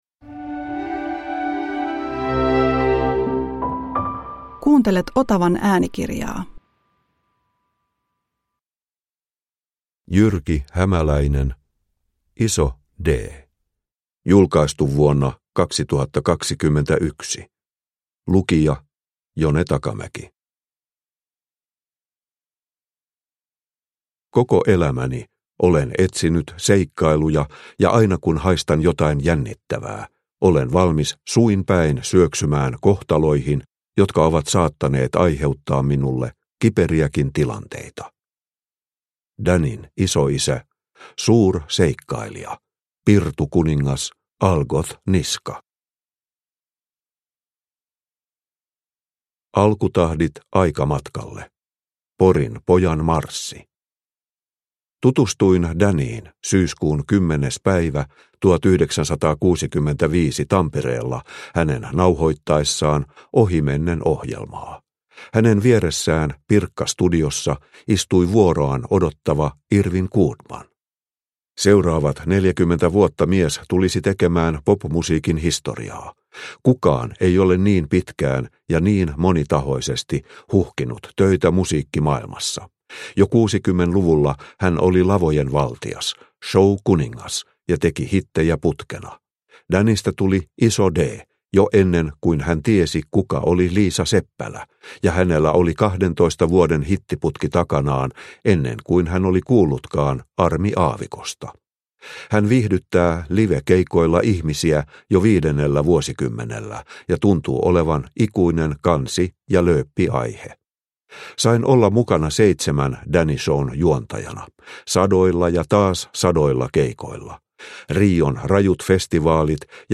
Iso D – Ljudbok – Laddas ner